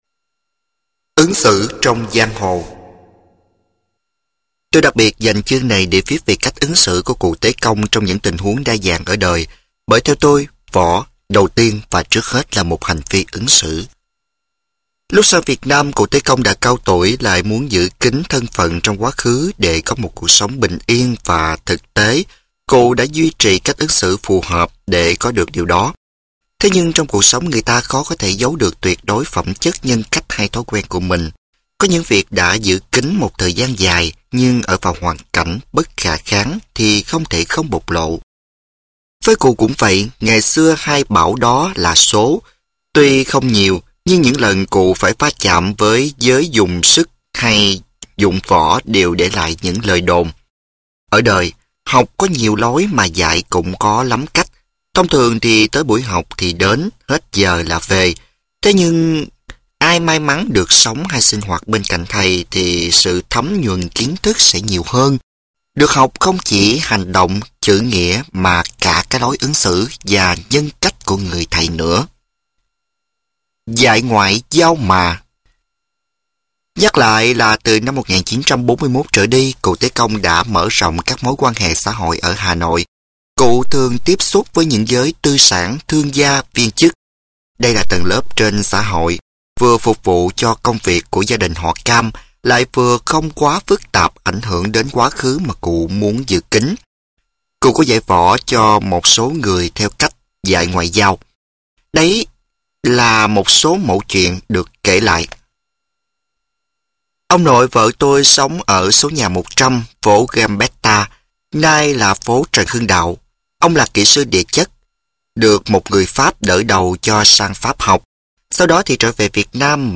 Sách nói Quyền Sư